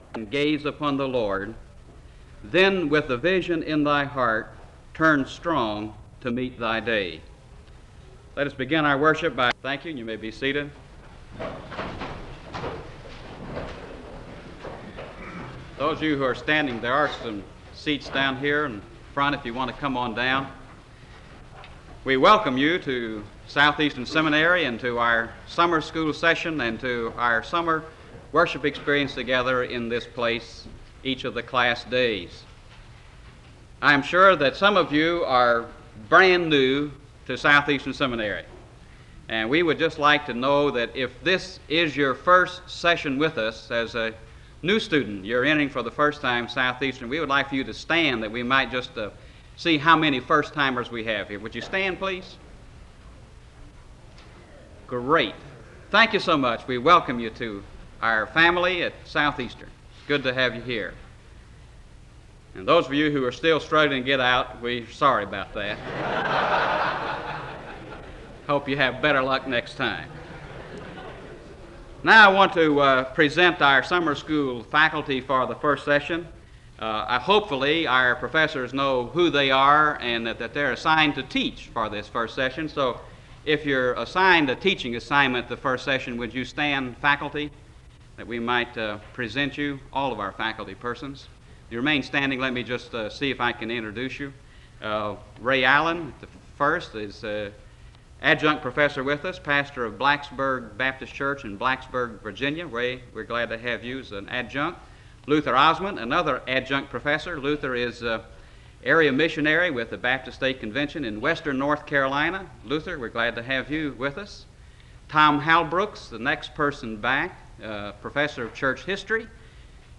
The service opens with a welcome of the faculty for the summer school session and a few announcements (00:00-05:59).